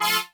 horn stab02.wav